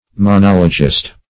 Monologist \Mo*nol"o*gist\, n. [See Monologue.]